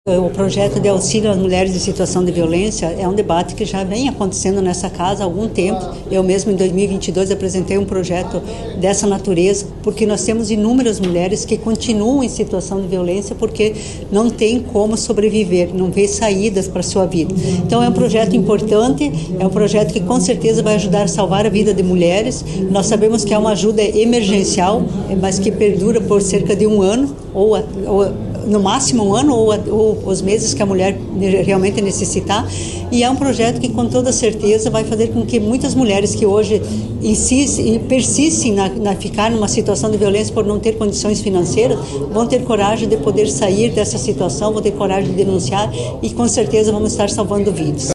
A deputada estadual Luciana Rafagnin (PT) disse que a busca por projetos específicos para proteção e auxílio de mulheres em situações vulneráveis já ocorre há muito tempo.